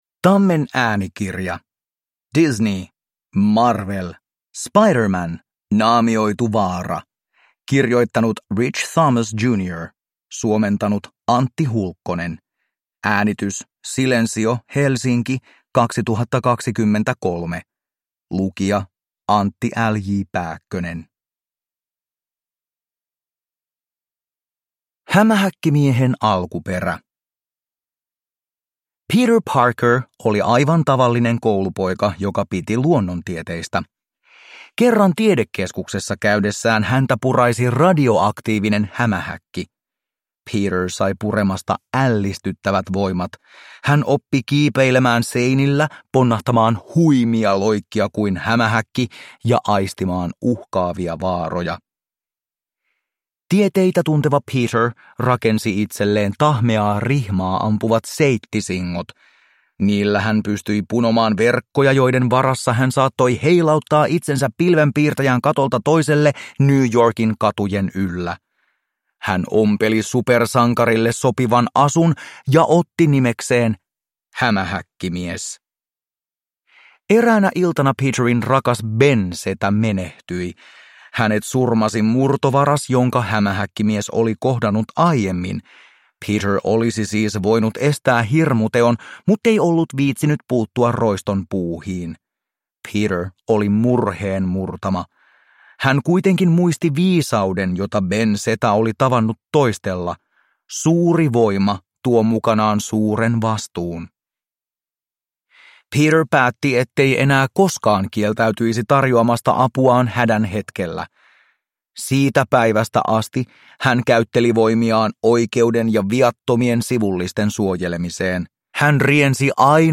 Marvel. Hämähäkkimies. Naamioitu vaara – Ljudbok – Laddas ner